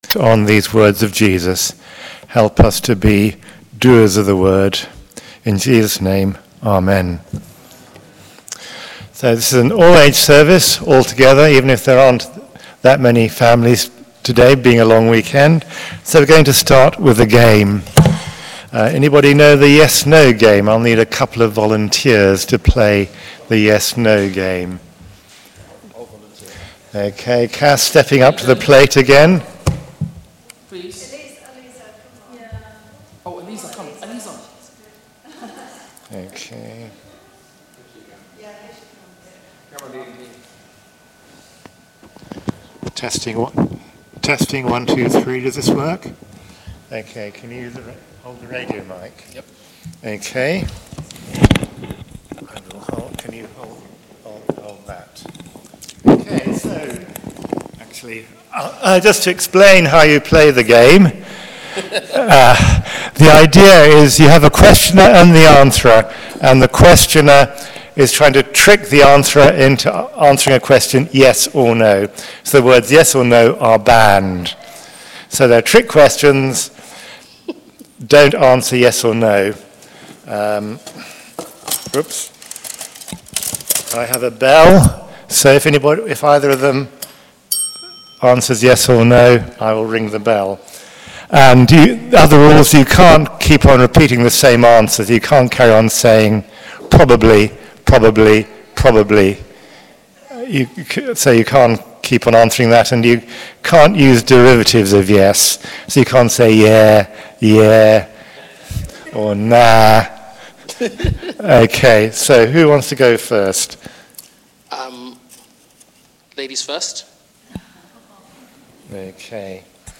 Listen to our 9.30am and 11.15am sermon here:
Passage: Matthew 22:15-22 Service Type: Worship Together